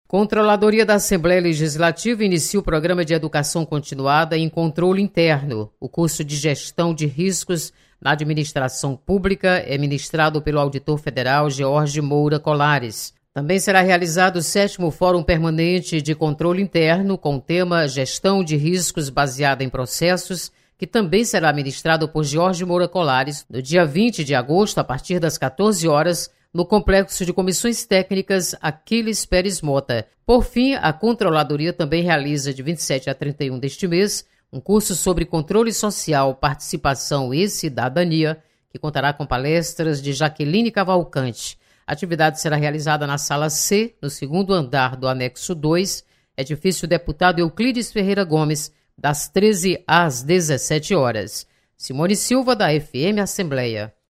Controladoria vai ministrar cursos de gestão e controle interno. Repórter